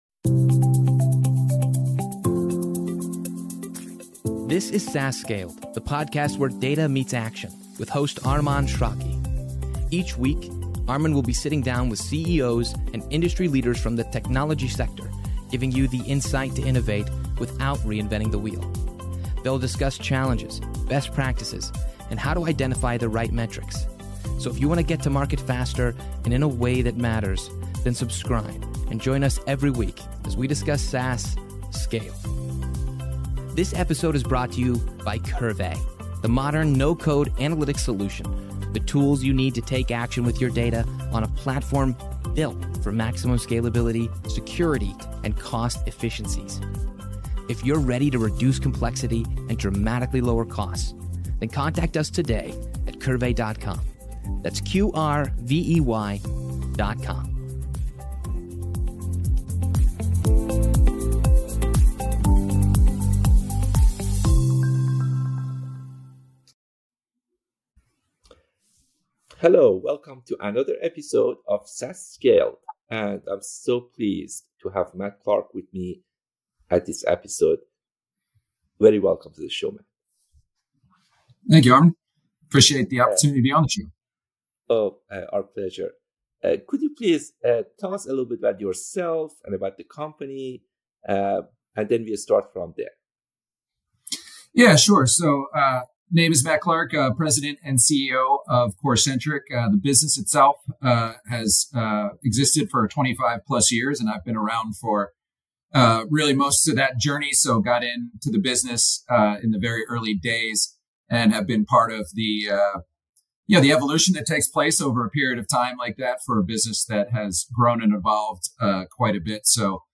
SaaS Scaled - Interviews about SaaS Startups, Analytics, & Operations